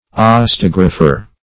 Osteographer \Os`te*og"ra*pher\, n. An osteologist.